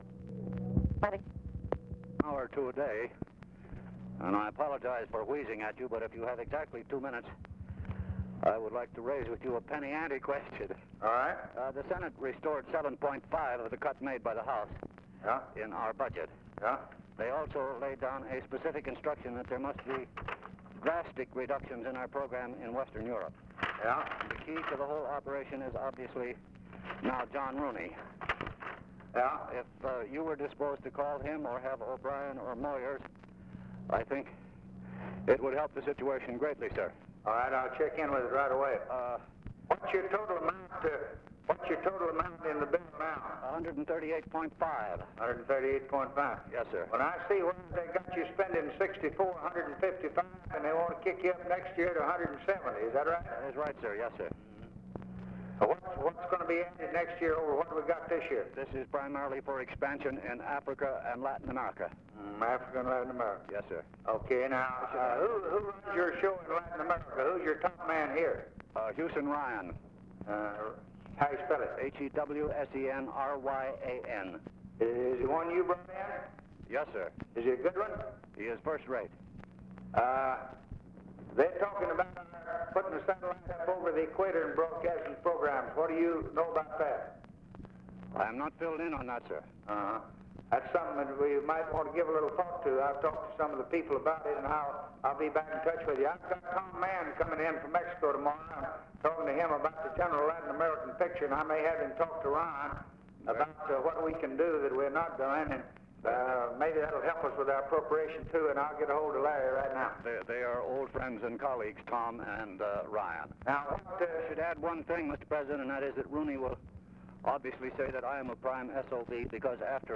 Conversation with EDWARD R. MURROW, December 09, 1963
Secret White House Tapes